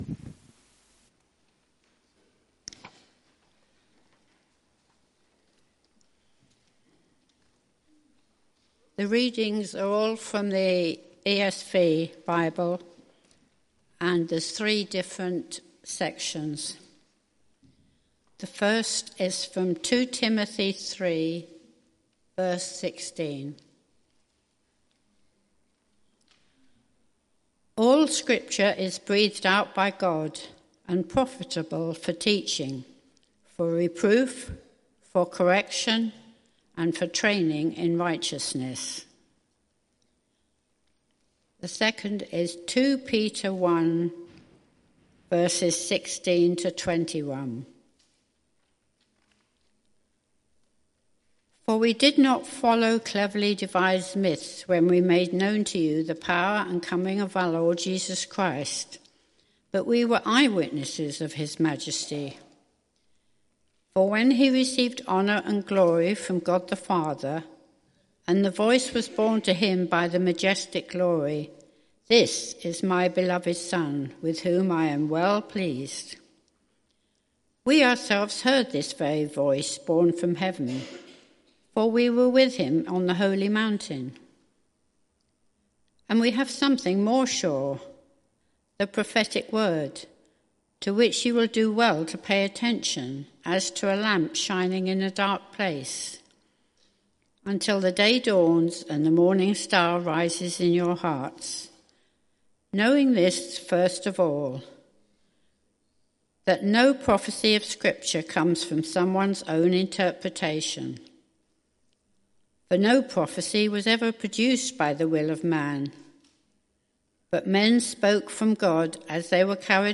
Sermon Series: The 5 Solas of the Reformation